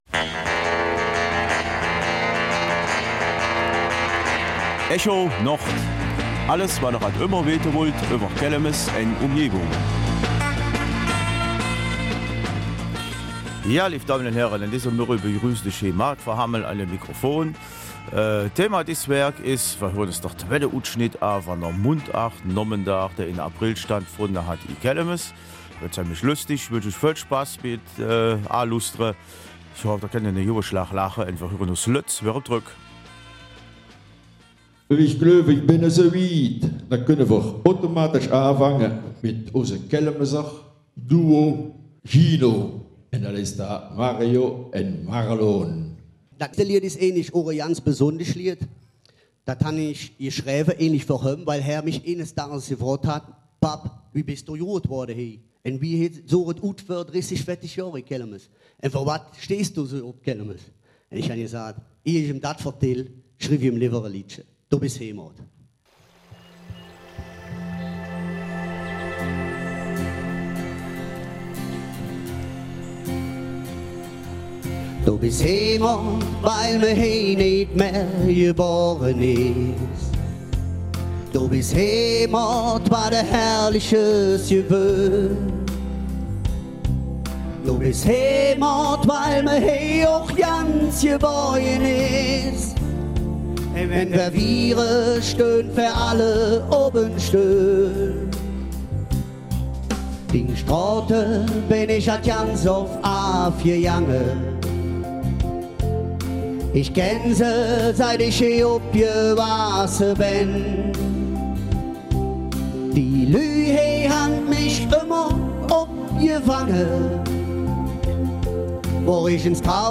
Kelmiser Mundart: Mundartnachmittag - Teil zwei